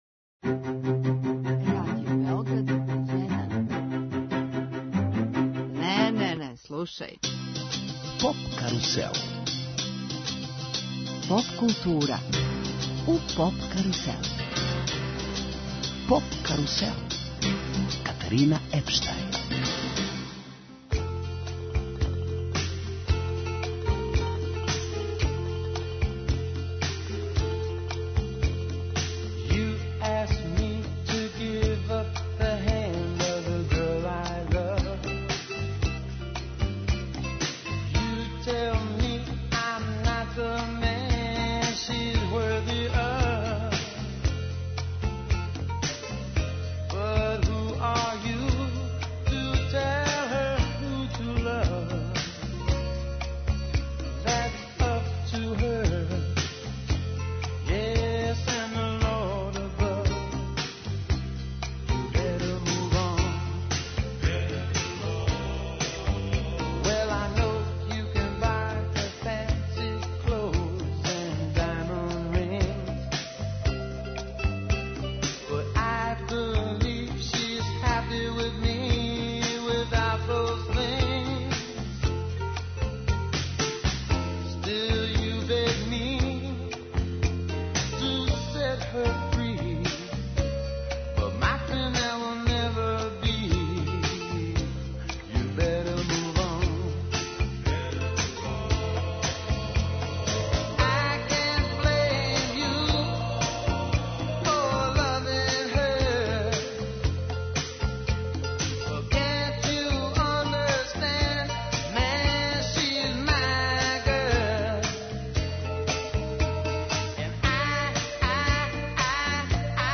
Гост емисије је кантаутор, сликар и песник